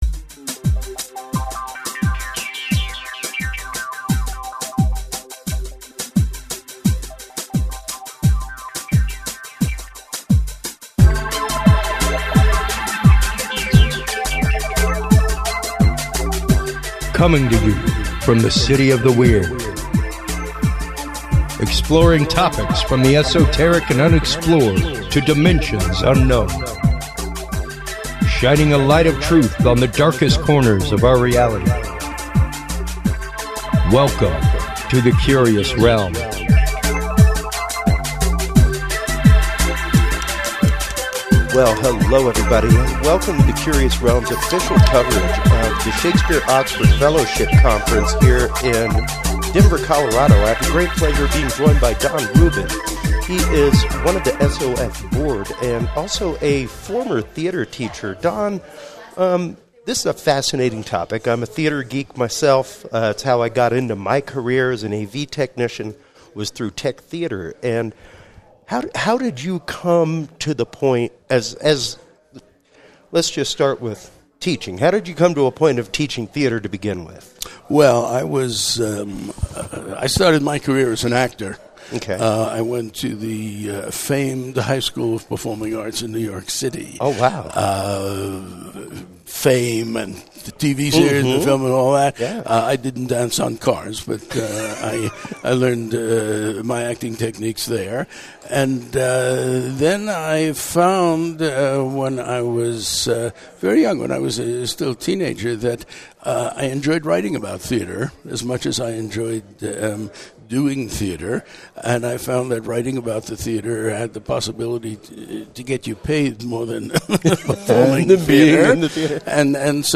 CR Ep 148: On Location at the 2024 Shakespeare Oxford Fellowship Conference - Curious Realm